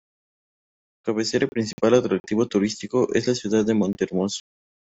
Pronounced as (IPA) /kabeˈθeɾa/